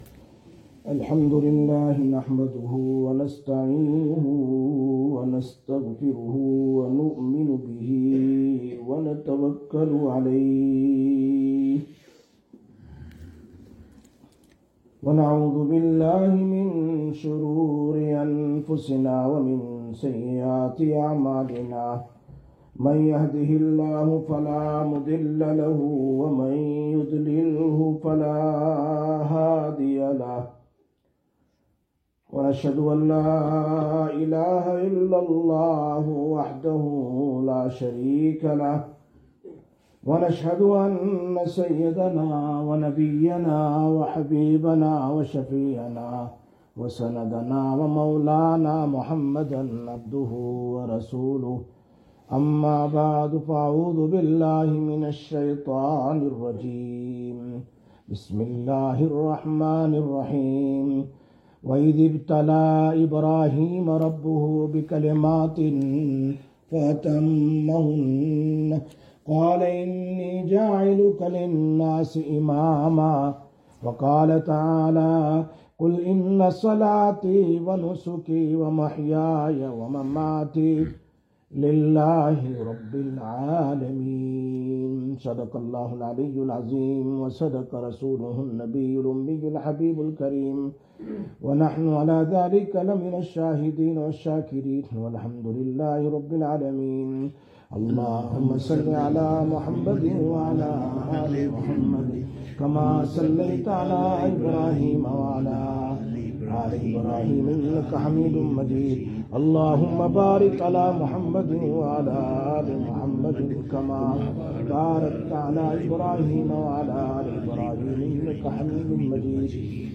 16/05/2025 Jumma Bayan, Masjid Quba